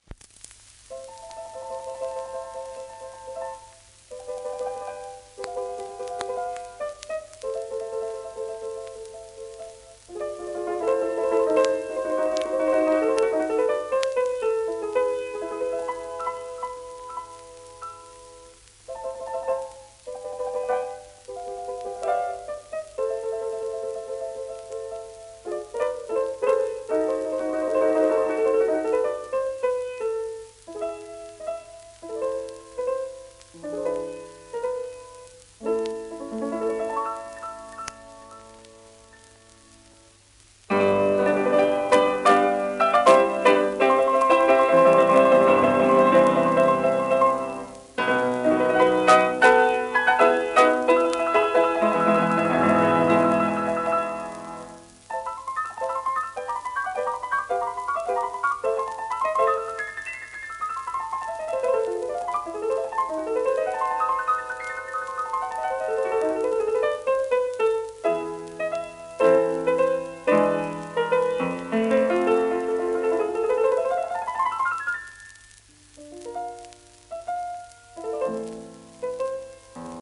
盤質A-/B+ *一部に軽度の溝白化
1935年録音